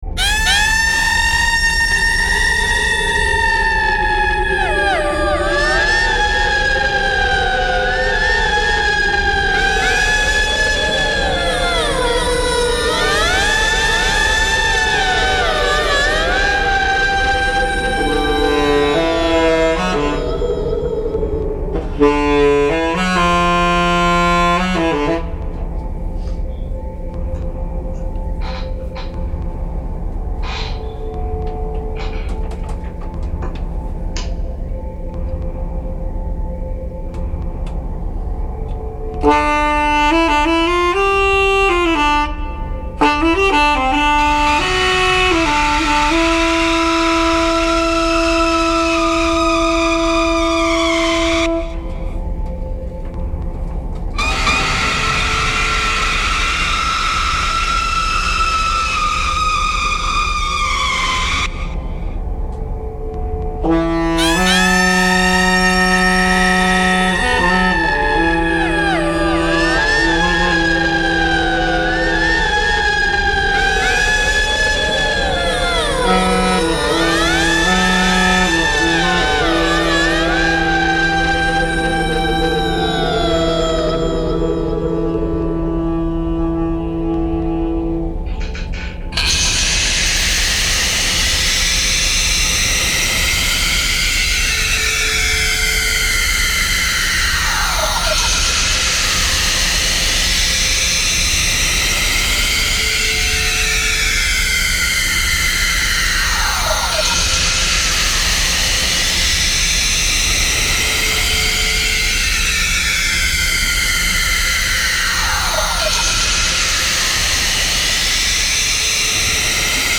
collaborative sound art practice